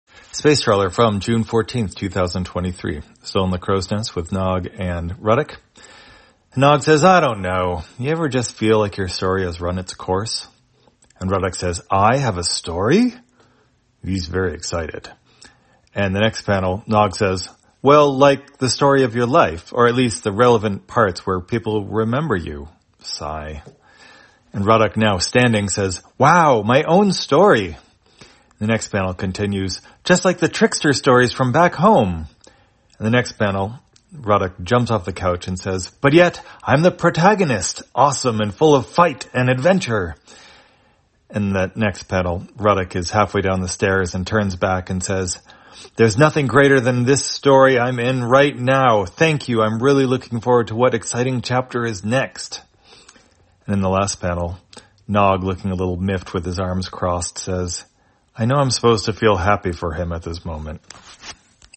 Spacetrawler, audio version For the blind or visually impaired, June 14, 2023.